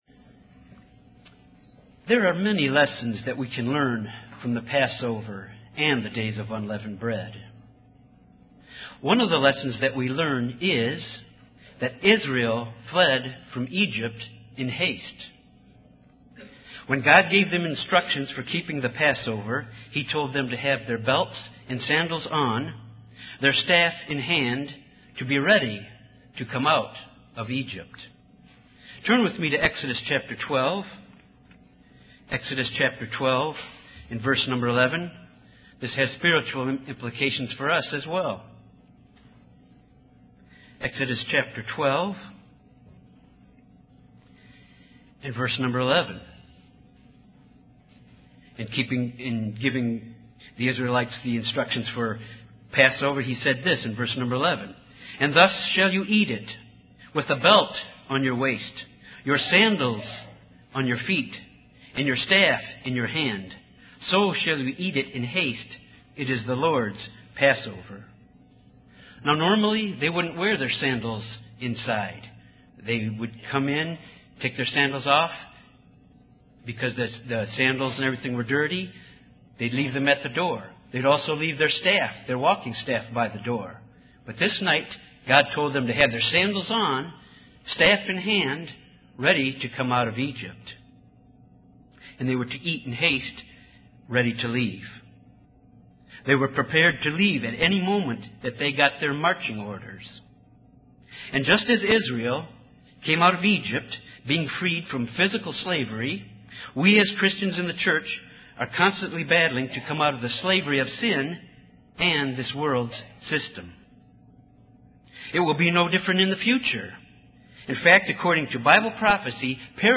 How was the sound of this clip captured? Given in Cincinnati East, OH